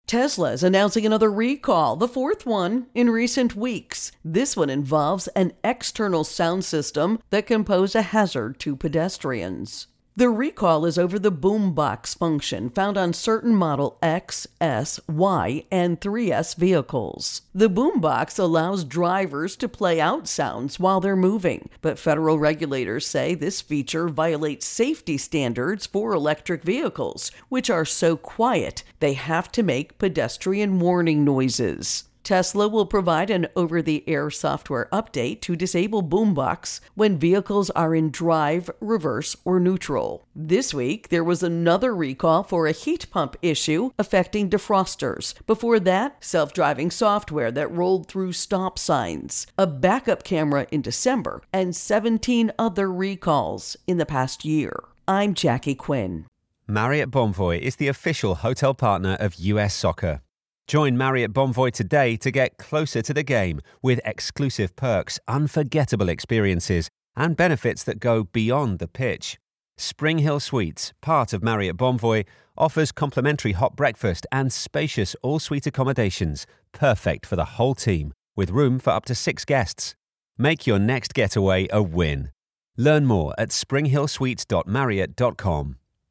Tesla Recall Boombox Intro and Voicer